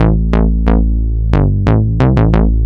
仪式性低音90Bpm A
描述：在fruitity loops中制作的戏剧性的更难的低音线。
Tag: 90 bpm Cinematic Loops Bass Loops 459.55 KB wav Key : A